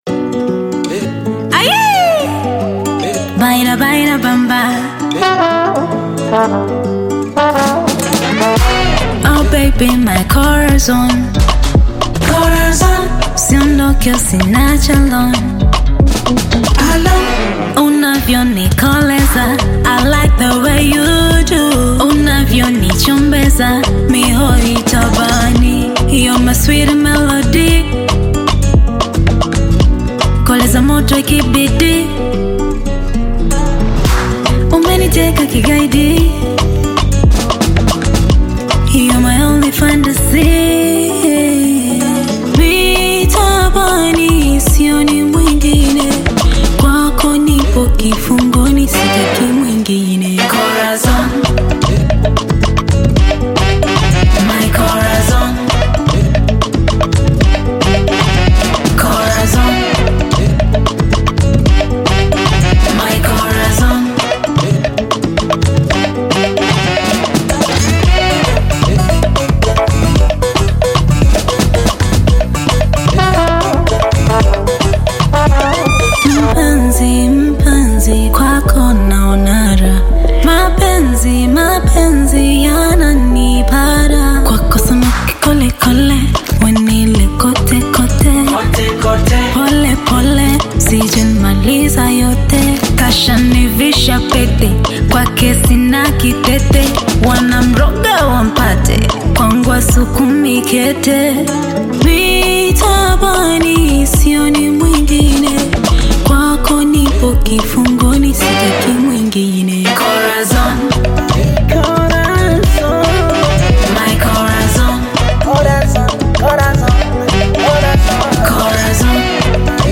Tanzanian Bongo Flava artist, singer and songwriter
Tanzanian Bongo Flava love song